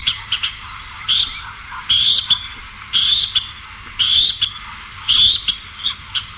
Grèbe à cou noir
Podiceps nigricollis